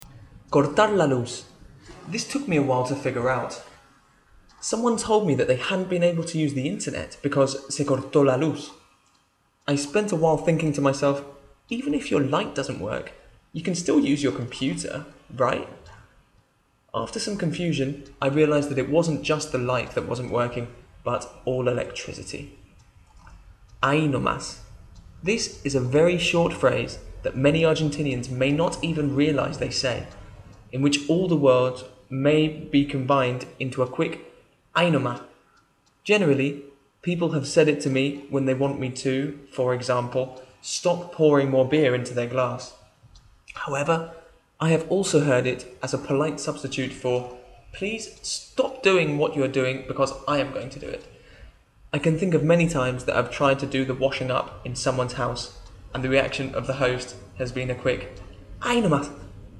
A short article about funny things Argentinians say. With audio in a British accent!